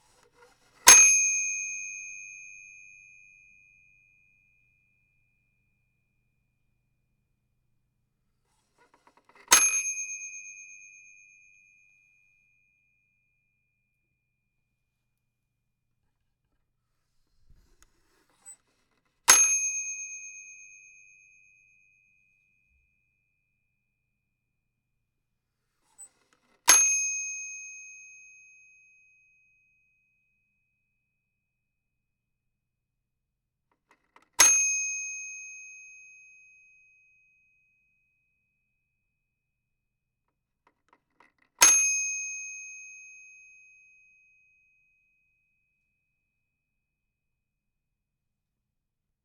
bell toy cash register ding
bell cash ding register toy sound effect free sound royalty free Sound Effects